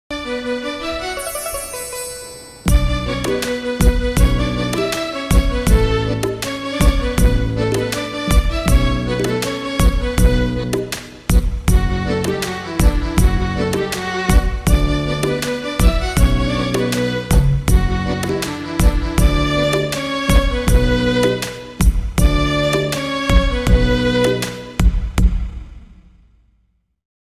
Instrumental Ringtone